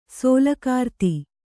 ♪ sōlakārti